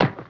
1 channel
ARROW14.WAV